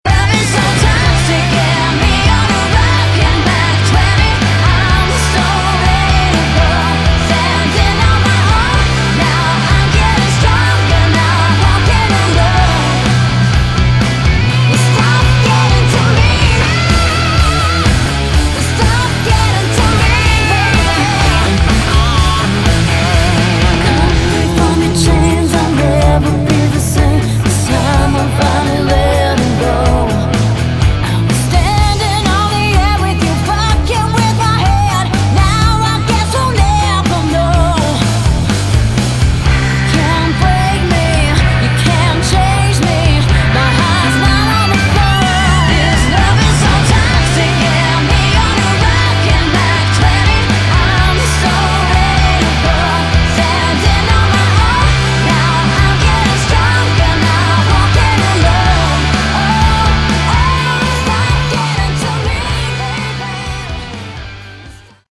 Category: Melodic Rock
vocals, guitars
bass, guitar, keyboards, piano, violin, backing vocals
drums